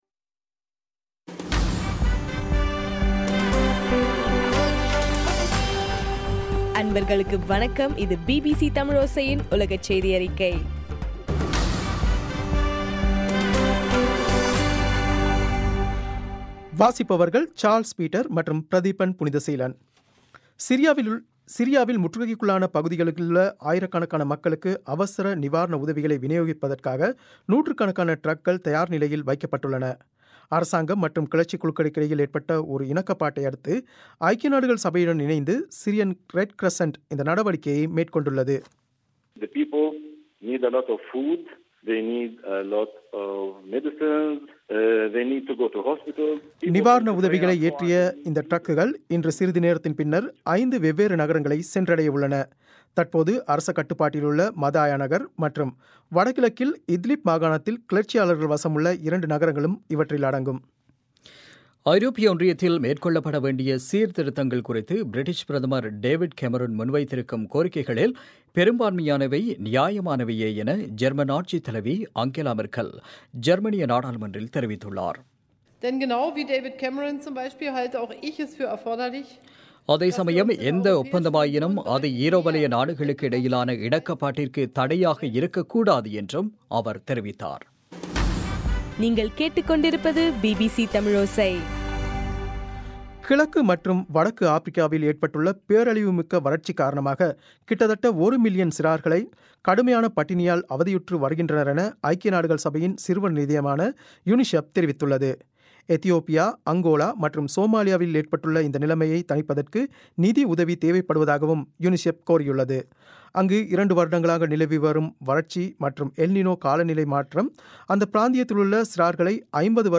பிபிசி தமிழோசை - உலகச் செய்தியறிக்கை- பிப்ரவரி 17